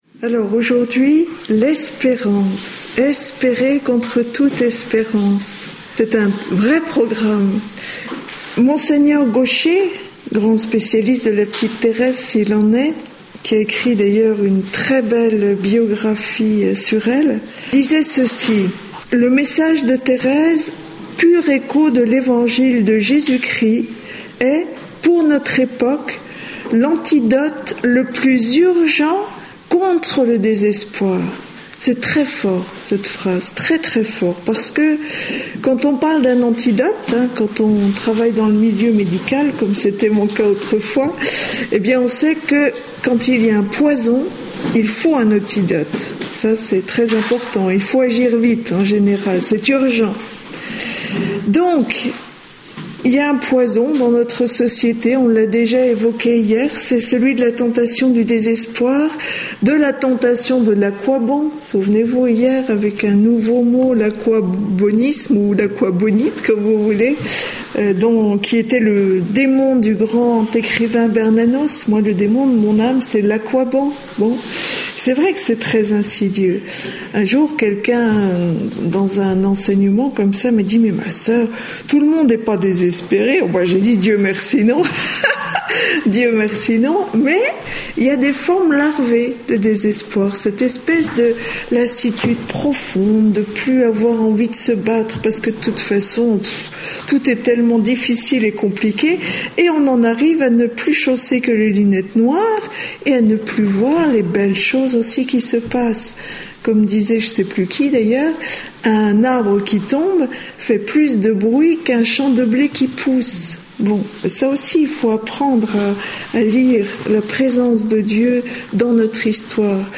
Enseignement
Atelier : à l'école de Thérèse, (Session Lisieux août 2012)
Enregistré en 2012 (Session des Béatitudes - Lisieux 2012)